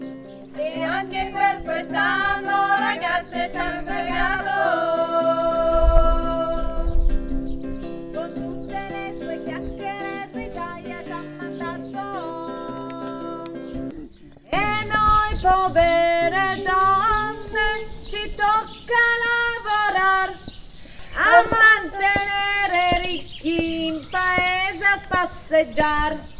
E_anche_per_quest_anno_SOPRANE.mp3